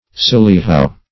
Search Result for " sillyhow" : The Collaborative International Dictionary of English v.0.48: Sillyhow \Sil"ly*how\, n. [Prov.